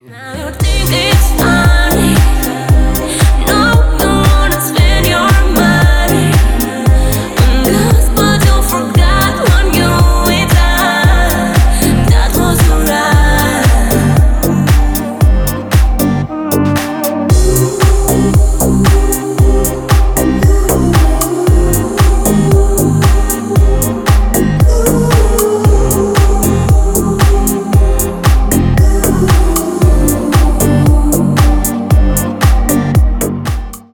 Поп Музыка # Танцевальные
клубные